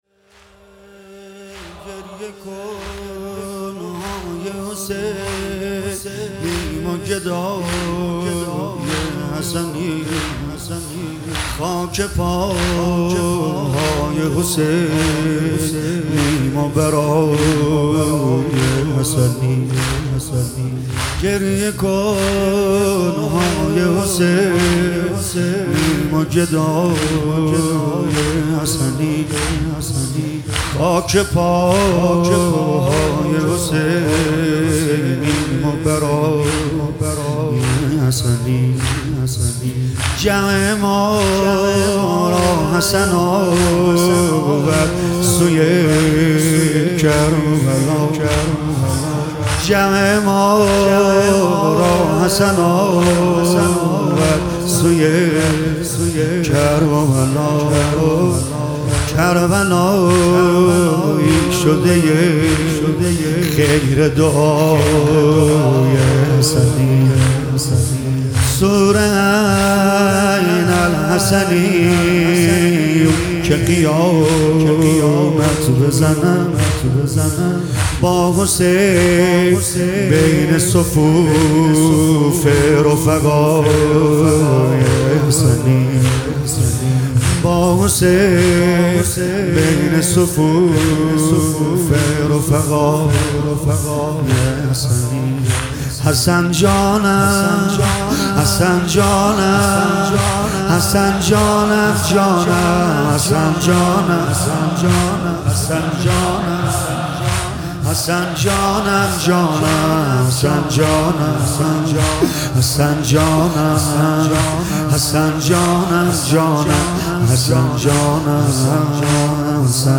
محفل عزاداری شب پنجم محرم ۱۴۴۵
مناجات روضه زمینه واحد واحد تک شور شور شور
مداحی